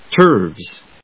音節turves発音記号・読み方tə́ːrvz